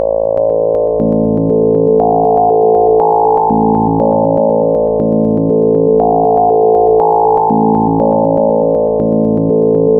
17-SEQ-DELAY-FORMANT-02.mp3